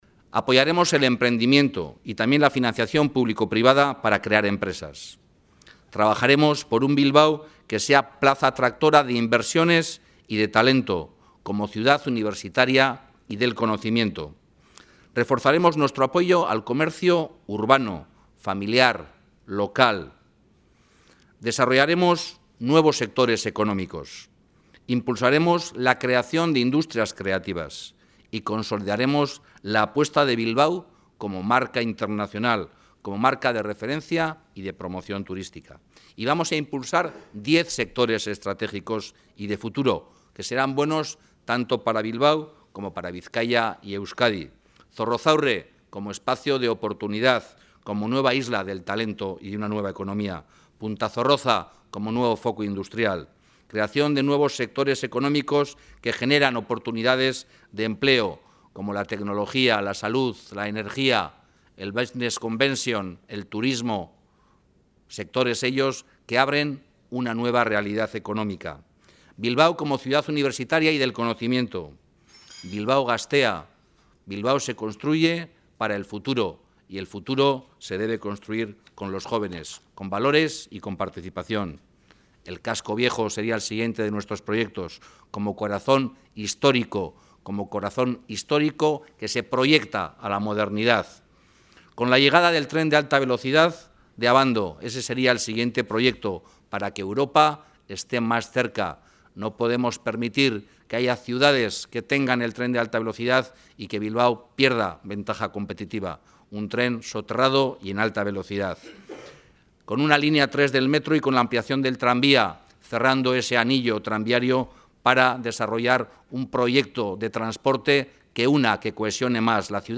Unai Rementeria, candidato de EAJ-PNV a diputado general de Bizkaia, y Juan Mari Aburto, candidato jeltzale a la Alcaldía de Bilbao, han mantenido esta mañana en el hotel Meliá de Bilbao el último encuentro sectorial en el que han presentado ante una veintena de representantes empresariales y del sector económico del territorio y la capital sus propuestas en materia de reactivación económica y generación de empleo.